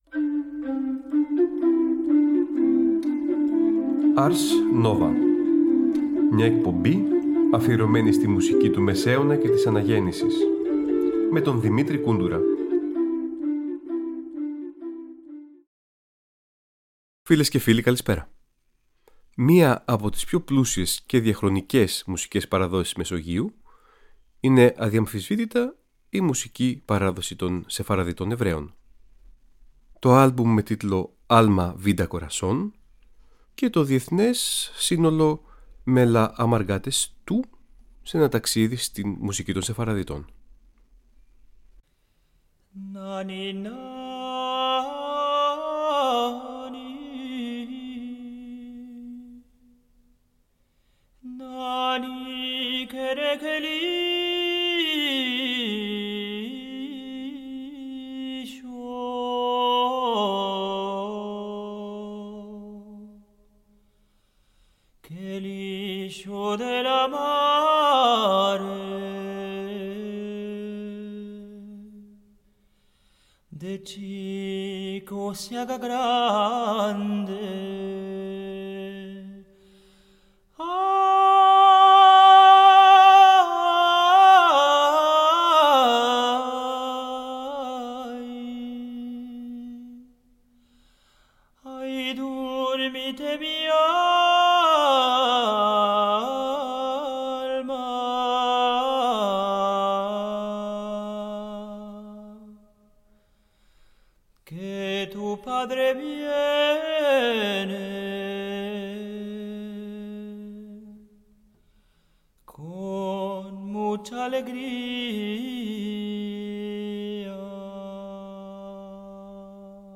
Σεφαραδίτικη παράδοση & Νεο-Μεσαιωνικές Μουσικές